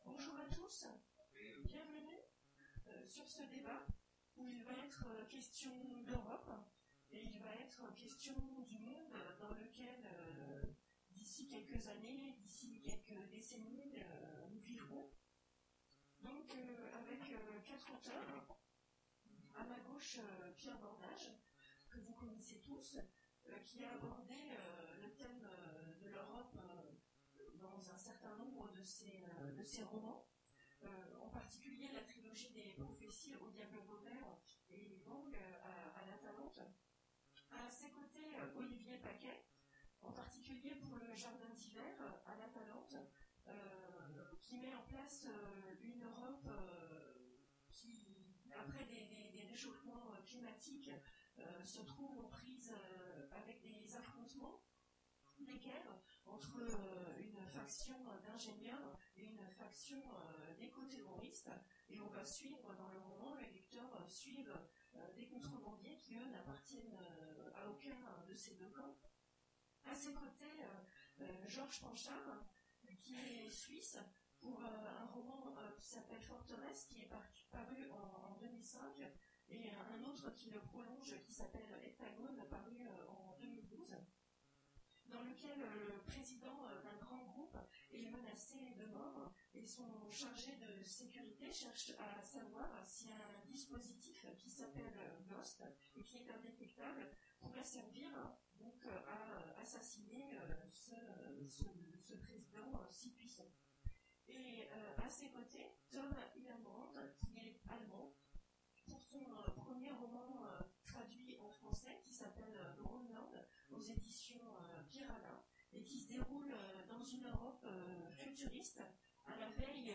Imaginales 2017 : Conférence L'Europe demain… Dans quel monde vivons-nous ?